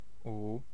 Ääntäminen
US : IPA : [ju]